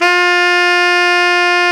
SAX TENORM0O.wav